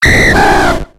Cri de Draco dans Pokémon X et Y.